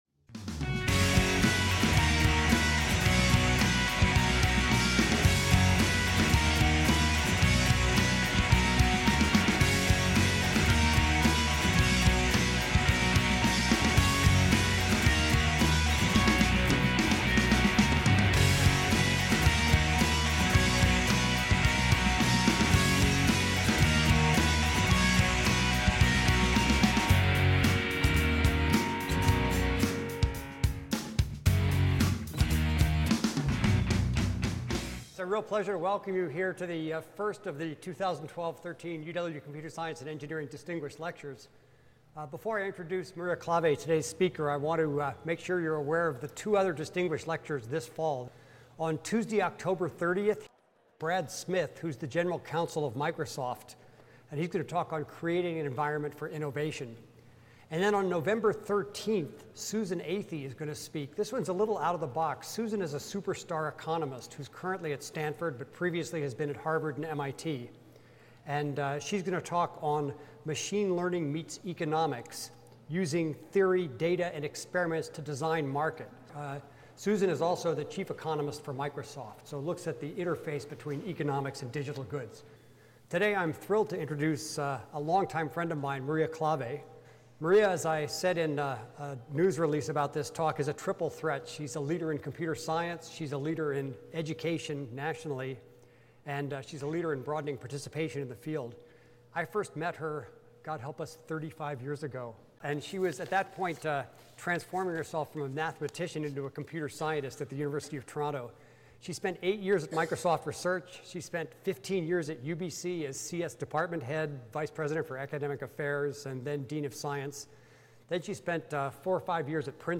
CSE Distinguished Lecture Series
Atrium, Paul G. Allen Center for Computer Science & Engineering